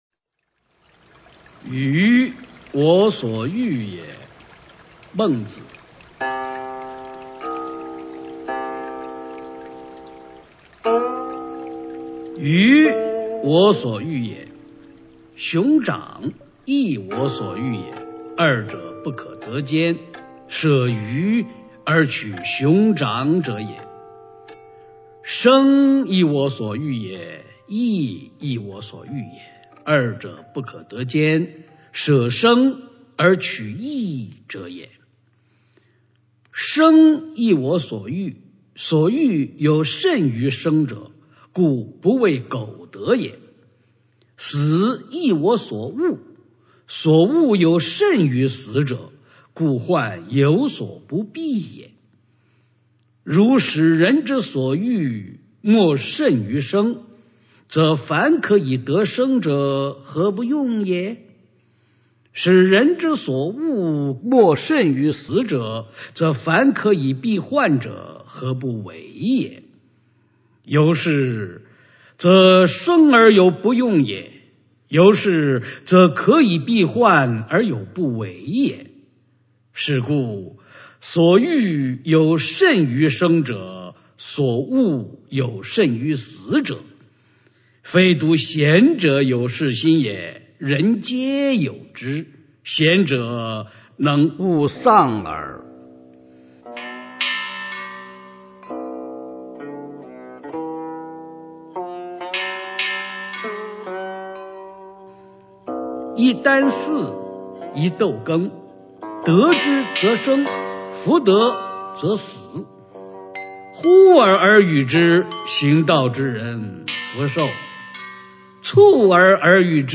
《鱼我所欲也》原文和译文（含朗读）